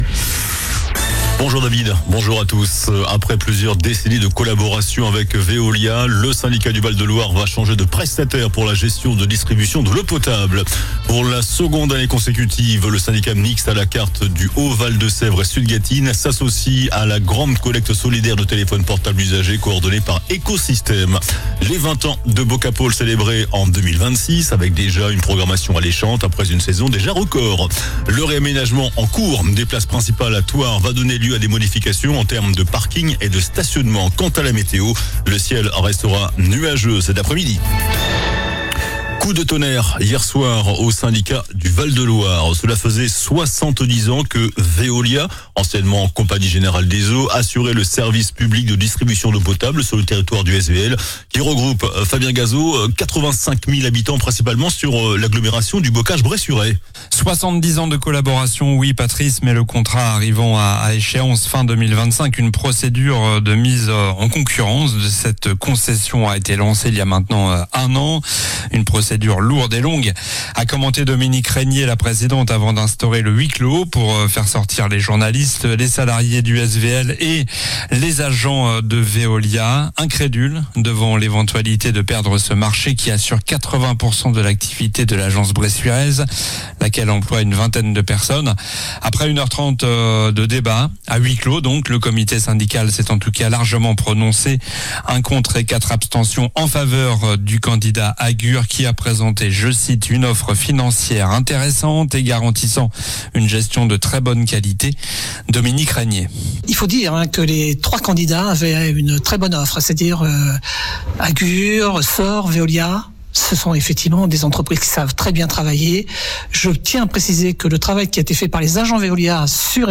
JOURNAL DU JEUDI 05 JUIN ( MIDI )